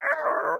minecraft-assets / assets / minecraft / sounds / mob / parrot / idle7.ogg